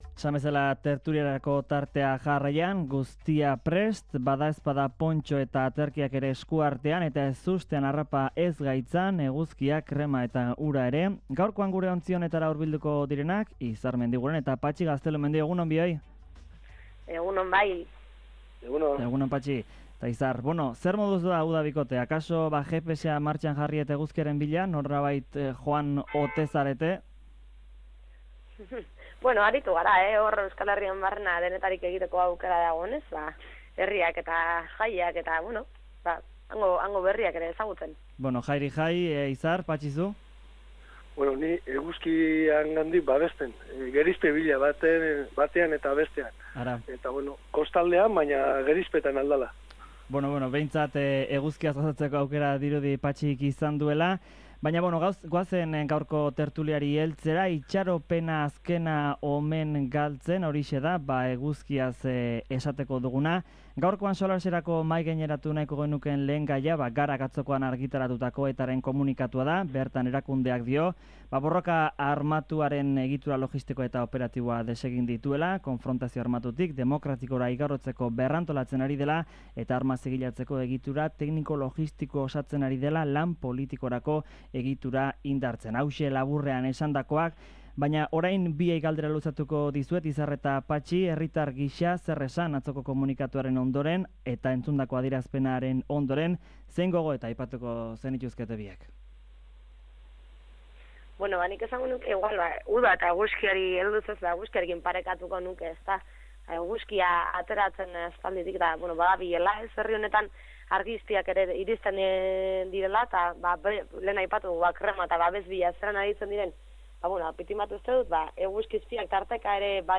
Tertulia Karelean saioan